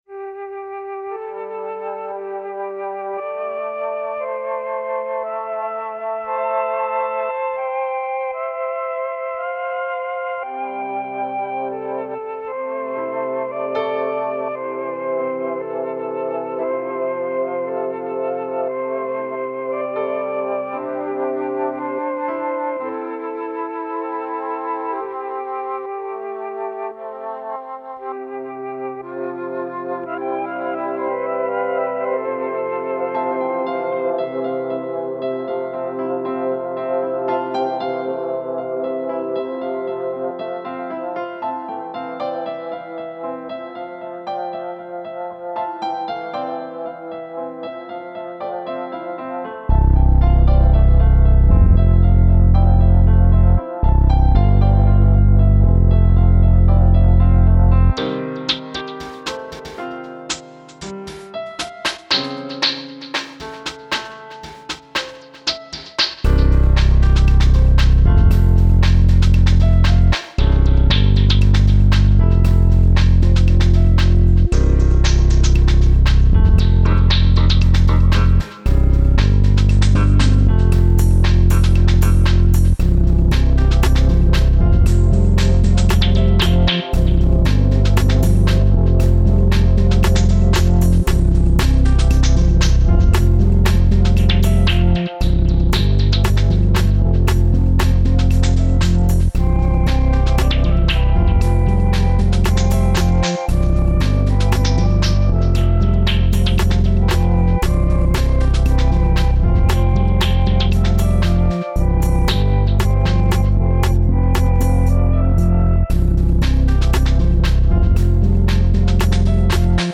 ambient, electronic, trip hop, downtempo, funk, cinematic